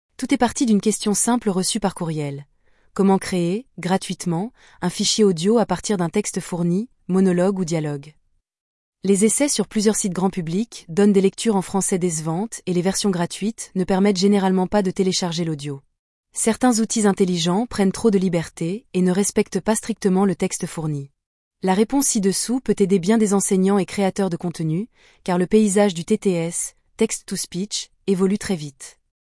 Petite démonstration :
# Voix féminines
5-fr-fr-vivienneneural.mp3